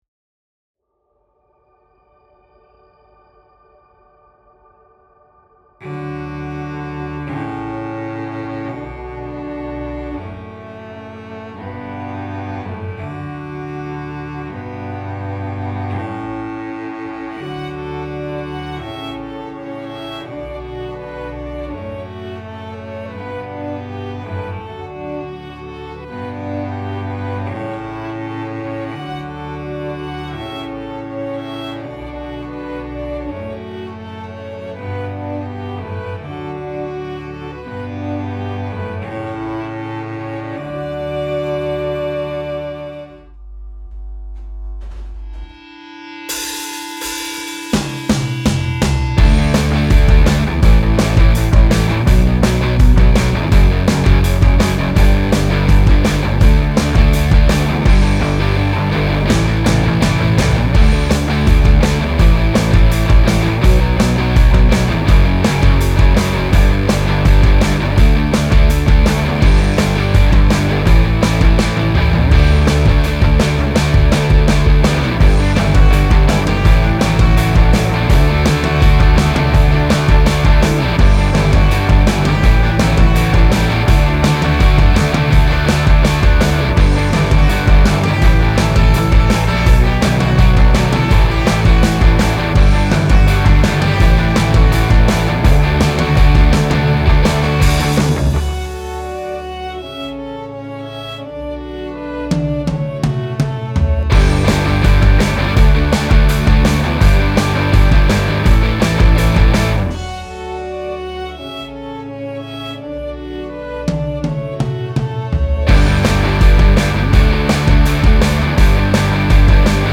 Version instrumentale playback